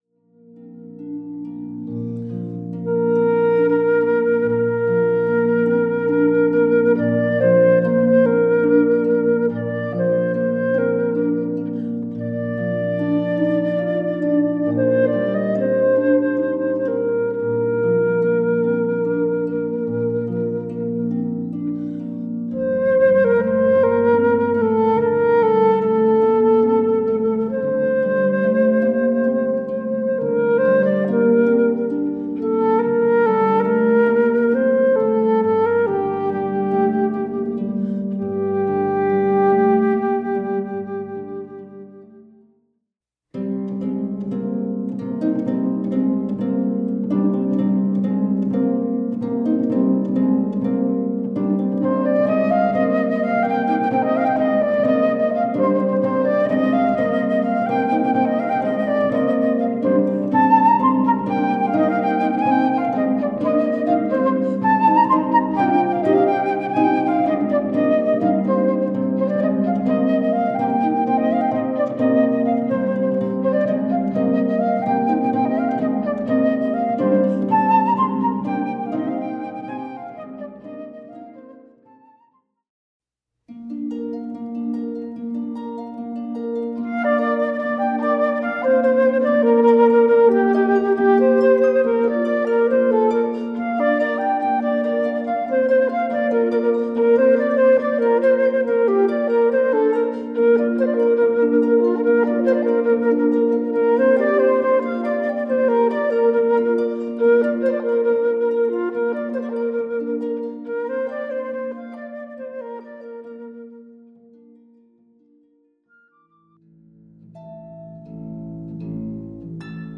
for lever or pedal harp and flute
eight Armenian folk tunes
flute